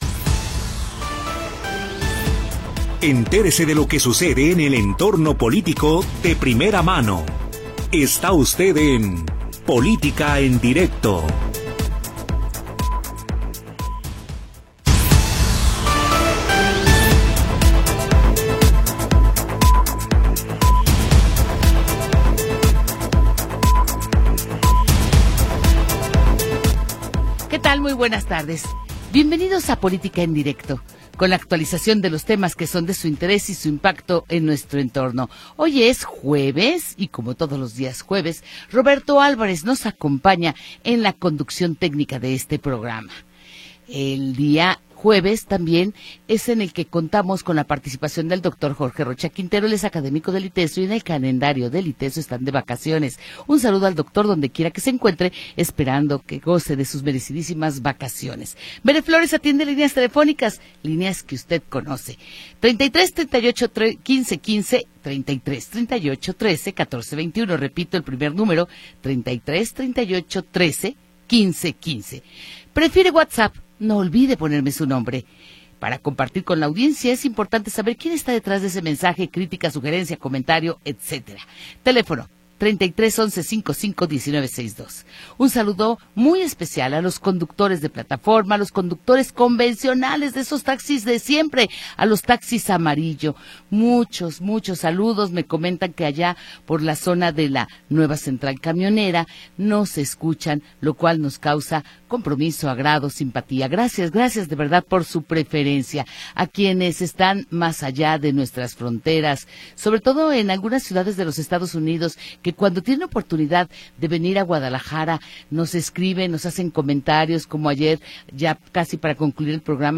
Programa transmitido el 24 de Julio de 2025.